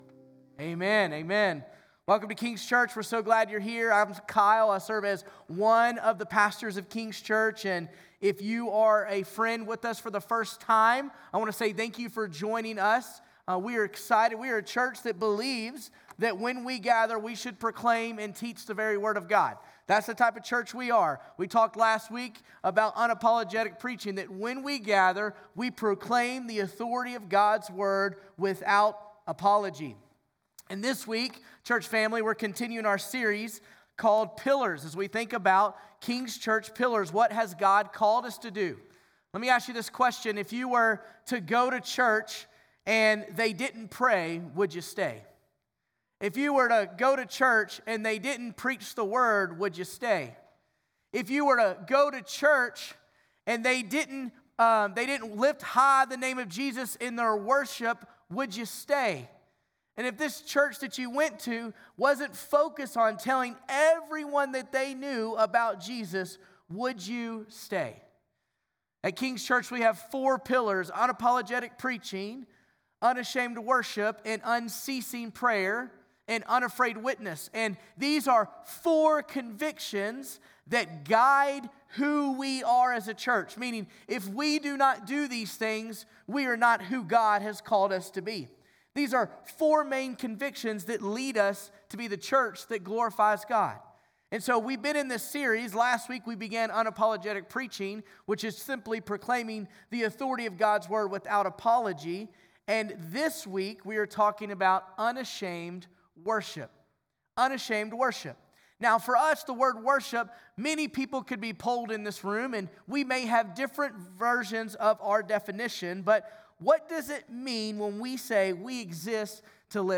June 8 Sermon.mp3